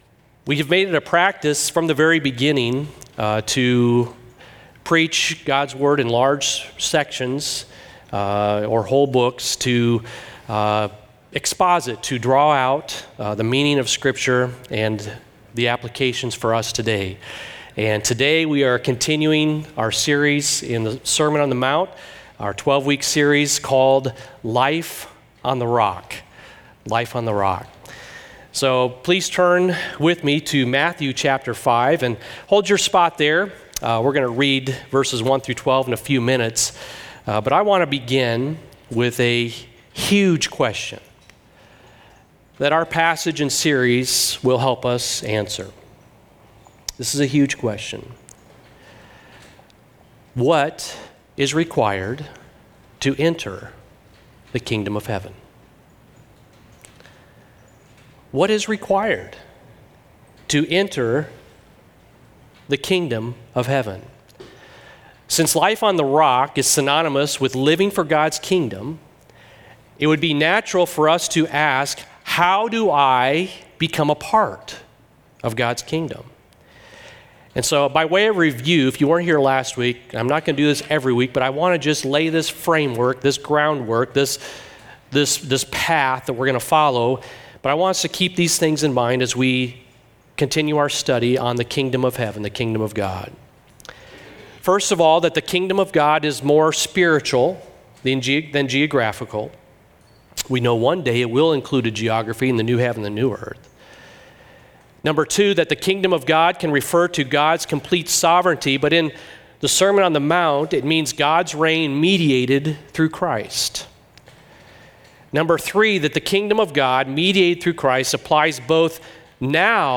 Sermon Notes: RLF_Sermon Notes_April_14_2024.docx